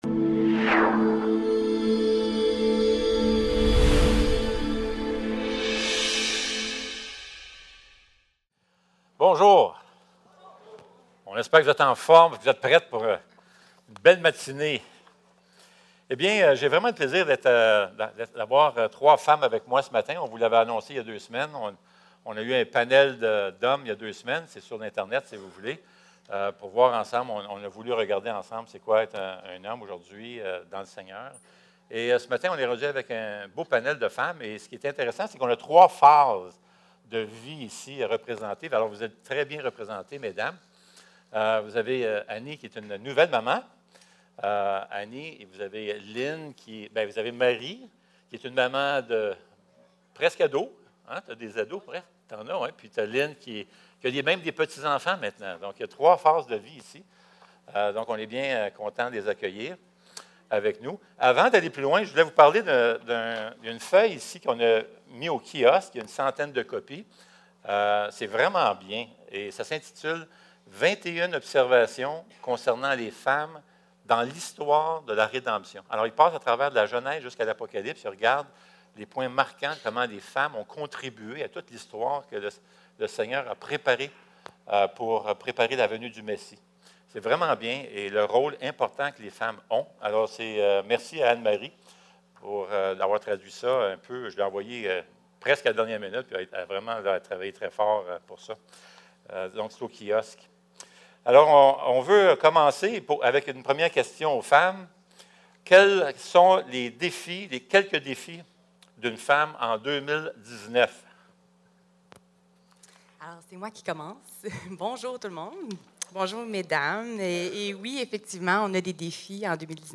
La maison de Dieu (10) - Les femmes et l'Évangile - Panel de discussion < église le Sentier | Jésus t'aime!